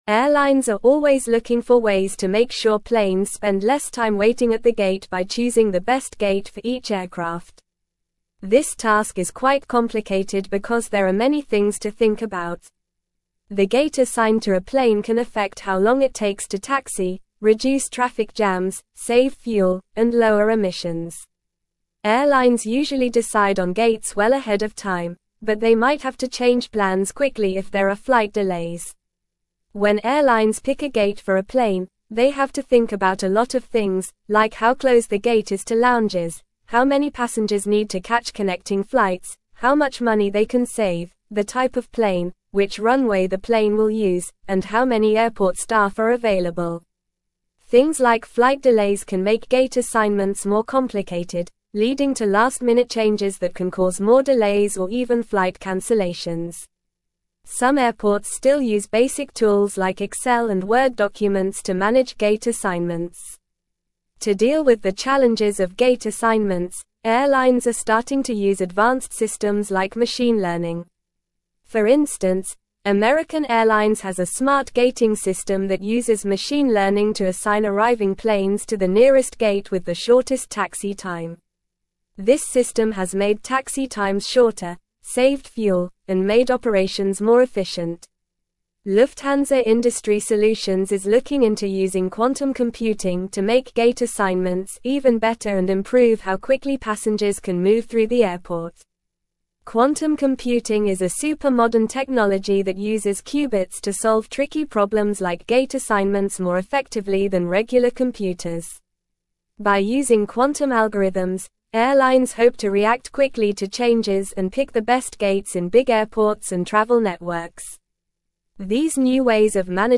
Normal
English-Newsroom-Upper-Intermediate-NORMAL-Reading-Efficient-Gate-Allocation-in-Airports-Innovations-and-Benefits.mp3